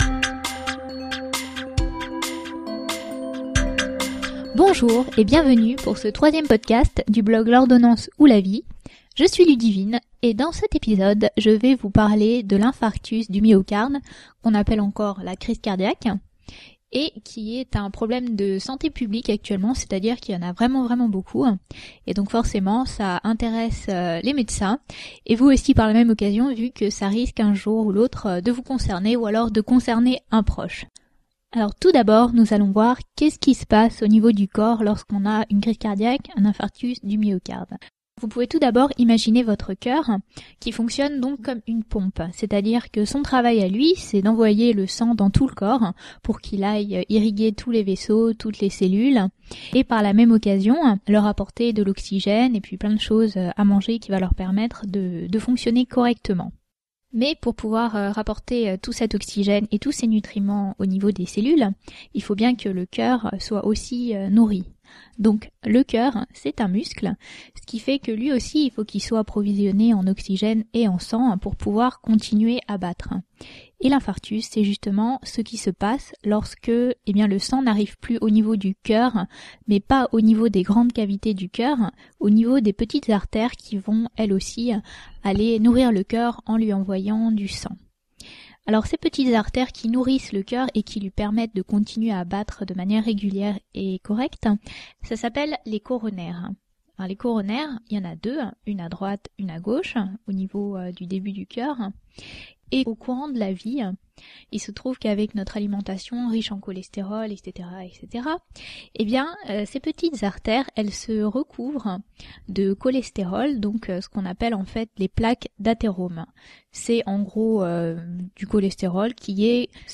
Podcast: Lire dans une autre fenêtre | Télécharger (Durée: 12:35 — 8.6MB) Dans cet épisode, je vous explique en langage aussi clair que possible le mécanisme de l’infarctus du myocarde ou crise cardiaque et les grandes étapes du traitement. Pour votre information, j’ai enregistré le podcast sans script ni plan, ce qui explique que mon discours ne soit pas d’une fluidité parfaite N’hésitez pas à me laisser vos avis dans les commentaires !